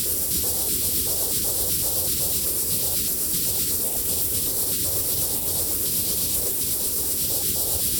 Index of /musicradar/stereo-toolkit-samples/Tempo Loops/120bpm
STK_MovingNoiseF-120_02.wav